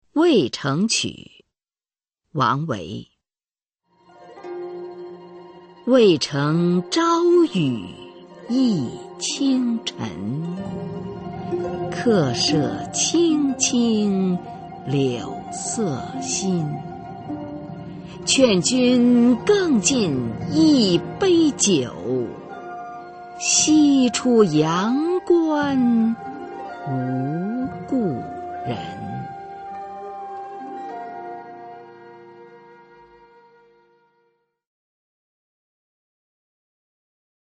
[隋唐诗词诵读]王维-渭城曲 配乐诗朗诵